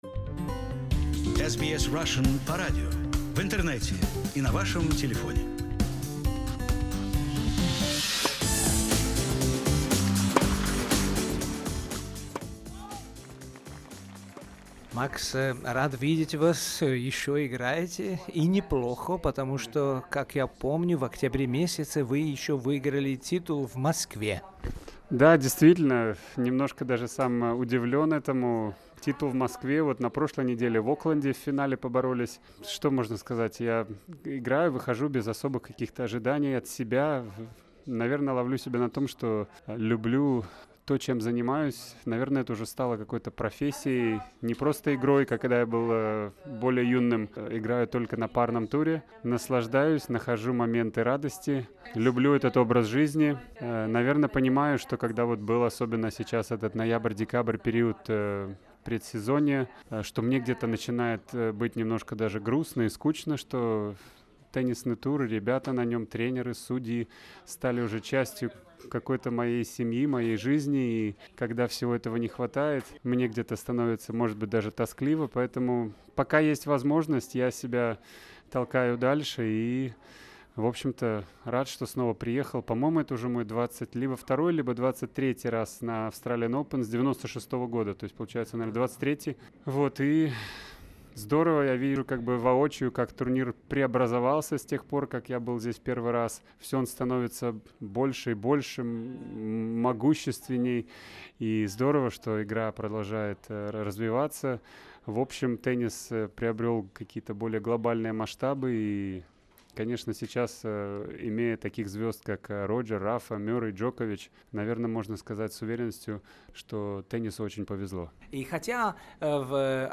After the match we spoke extensively with Max Mirnyi who's contribution to development of tennis in Belarus it is difficult to overestimate. And today we bring you the first part of our interview about the growth of the Australian tournament and reminiscing on some pages of the history of Belorussian tennis.